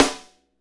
Index of /90_sSampleCDs/Northstar - Drumscapes Roland/DRM_Funk/SNR_Funk Snaresx